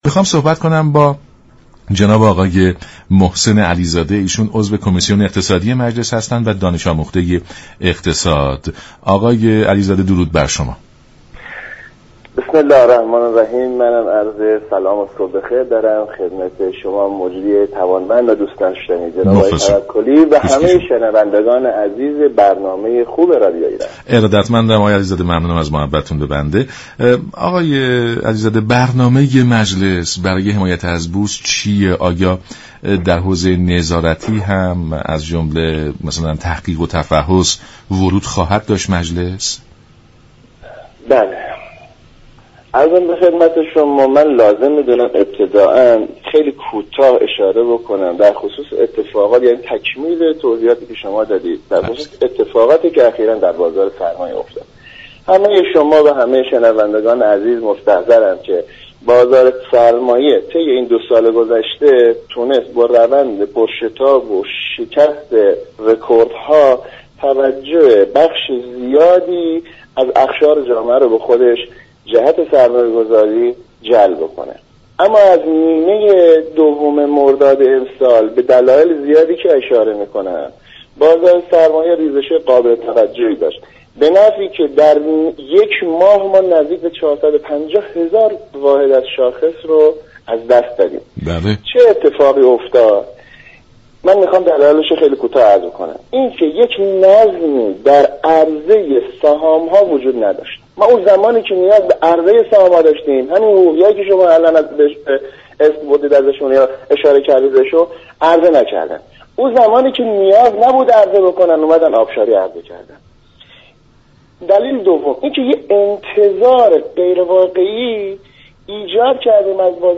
به گزارش شبكه رادیویی ایران، «محسن علیزاده» عضو كمیسیون اقتصادی مجلس در برنامه سلام صبح بخیر رادیو ایران در پاسخ به این پرسش كه برنامه مجلس برای حمایت بورس چیست؟ گفت: طی دو ماهه اخیر كه بازار سرمایه با كاهش شدید شاخص بورس و سهام روبرو بوده مجلس با جدیت وارد كار شده و از كارشناسان و صاحبنظران این حوزه، برای بهبود وضعیت بازار راهكار خواسته است.